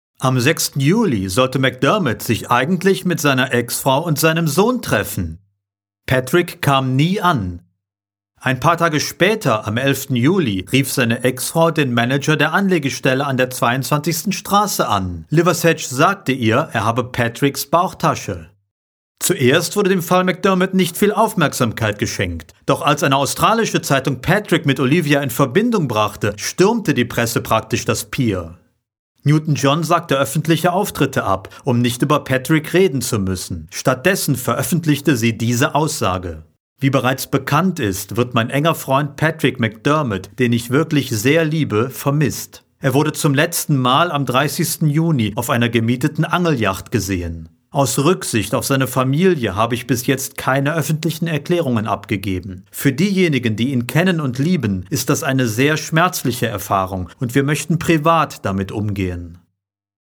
Sprechprobe: Werbung (Muttersprache):
German voice over talent and musician